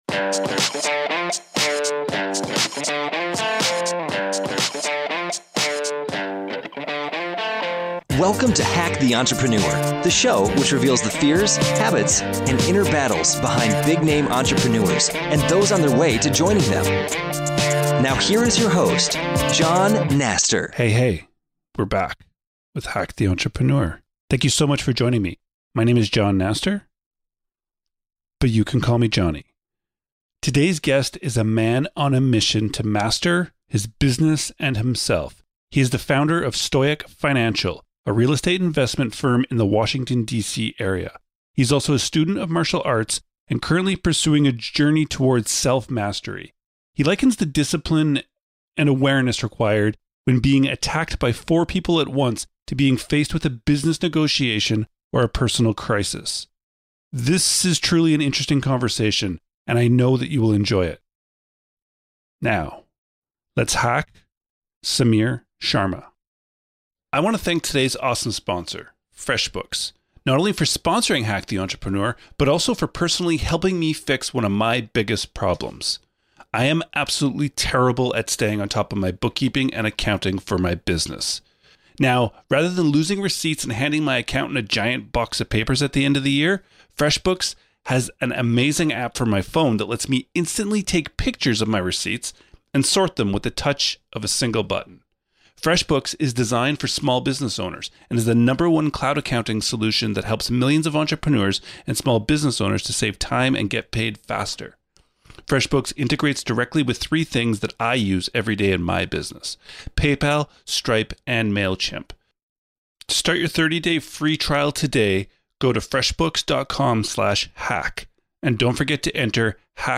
This is an truly interesting conversation that I know you will enjoy.